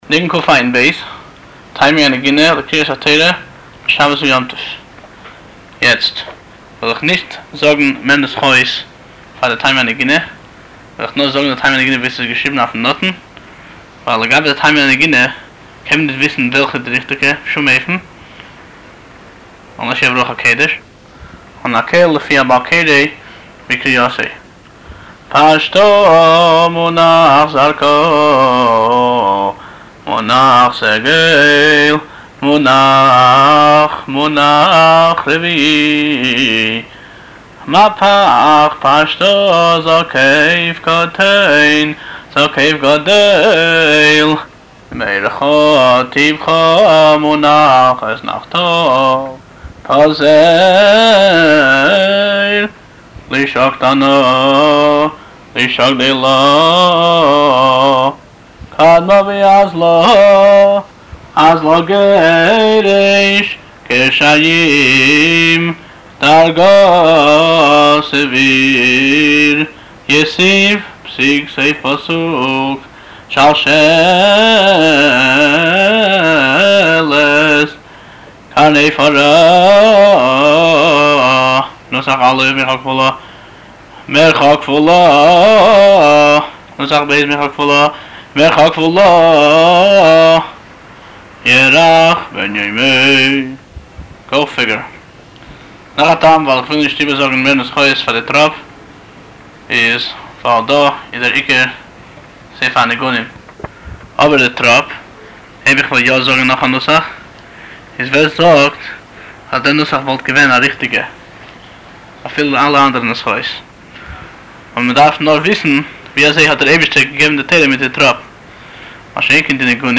טעמי הנגינה - נוסח חב"ד
טעמי הקריאה של שבת ויום טוב וימות החול